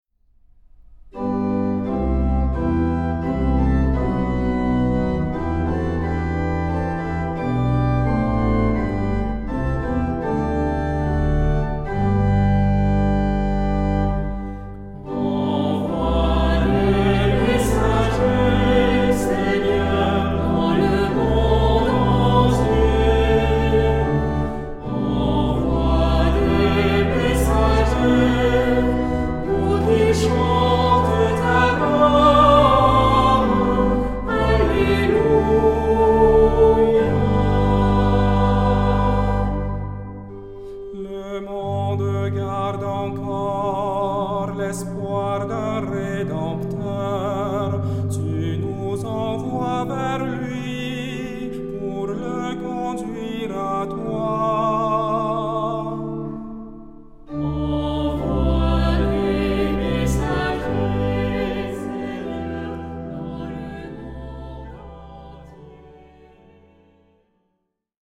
Genre-Stil-Form: Kirchenlied
Charakter des Stückes: lebhaft
Chorgattung: SATB  (4 gemischter Chor Stimmen )
Instrumente: Orgel (1) ; Melodieinstrument (ad lib)